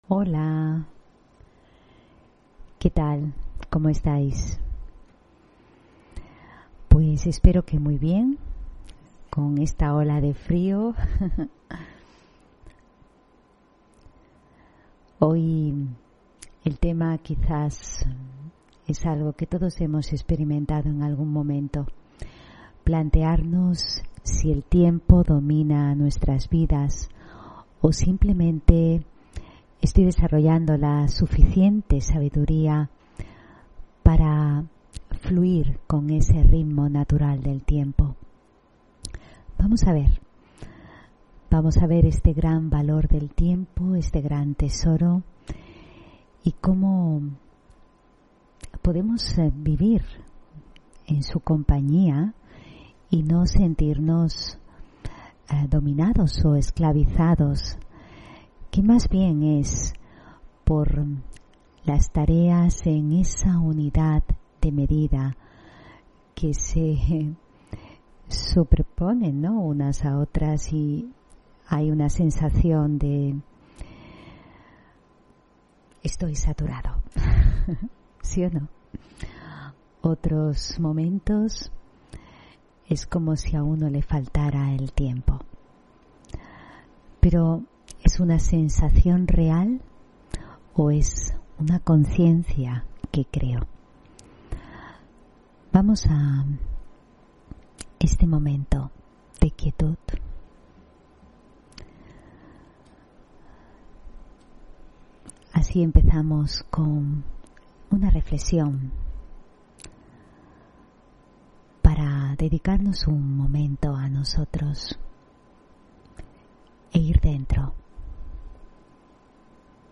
Audio conferencias
Meditación y conferencia: Amo o esclavo del tiempo (24 Noviembre 2021)